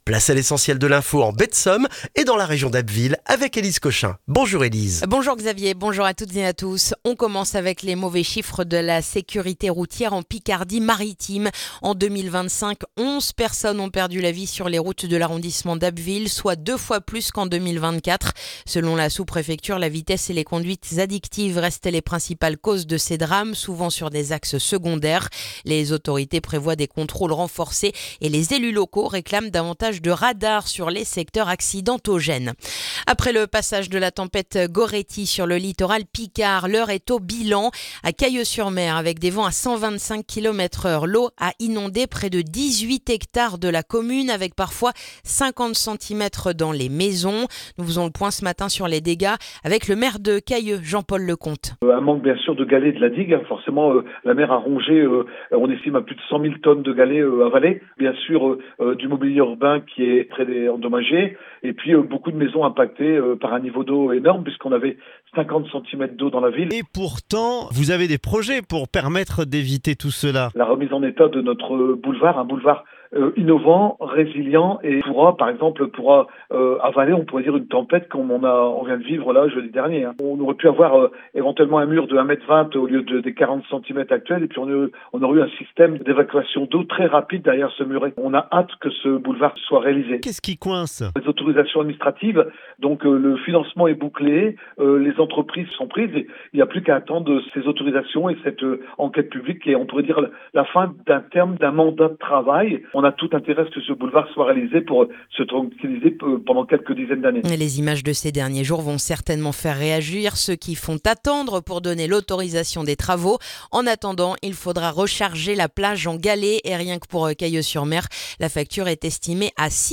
Le journal du mardi 13 janvier en Baie de Somme et dans la région d'Abbeville